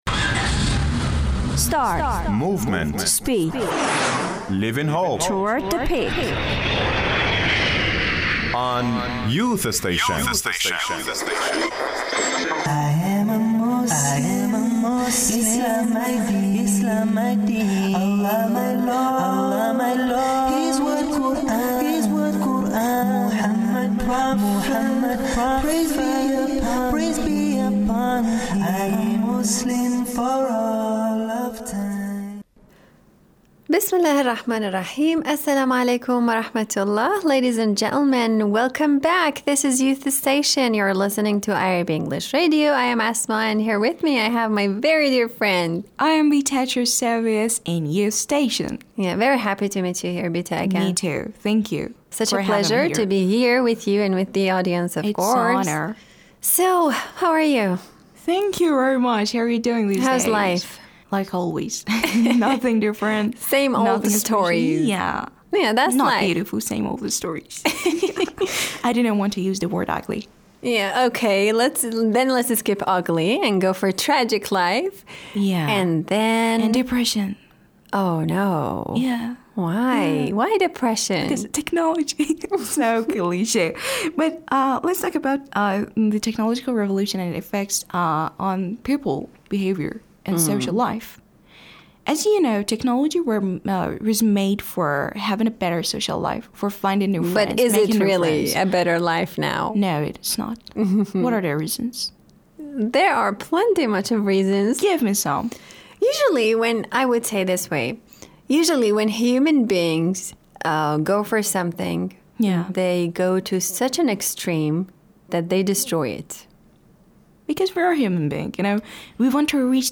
Muslim Youth speak about Depression and Tech